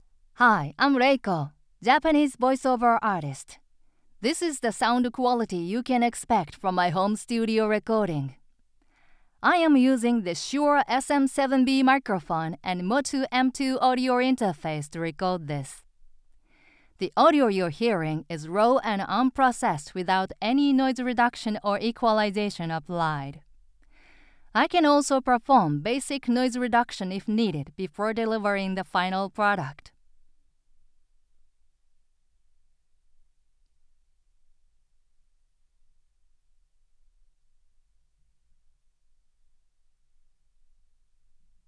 Female
Assured, Authoritative, Confident, Cool, Deep, Engaging, Friendly, Natural, Warm, Witty, Versatile
American English with Japanese accent Japanese with Inaba dialect
Voice reels
Microphone: RODE NT1, SHURE SM7B